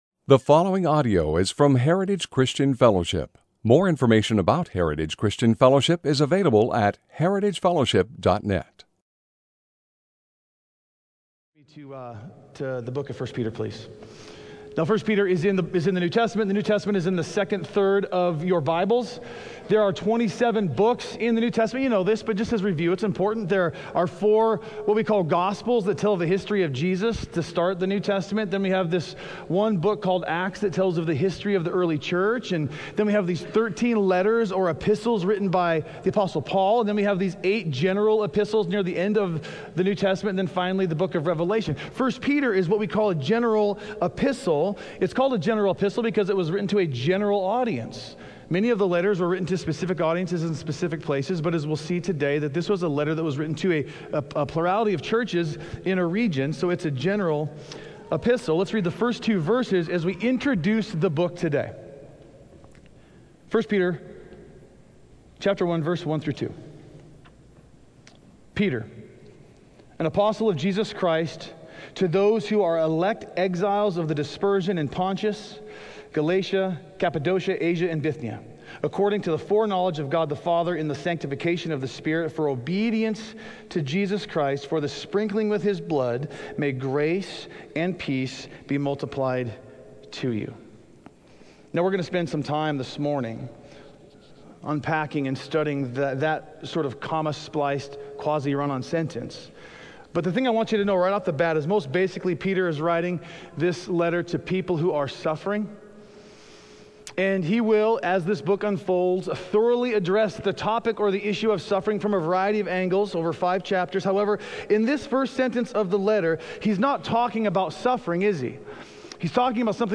A message from the series "Hope In Exile." 1 Peter 5:1-5